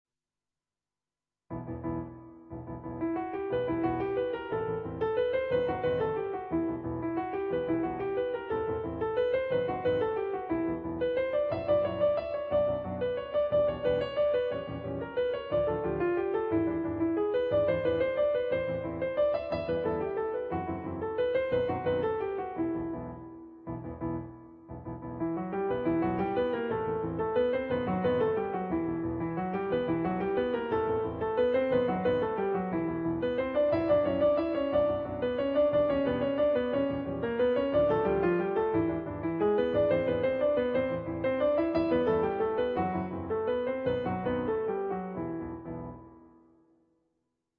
adapted for Two Pianos
on Yamaha digital pianos.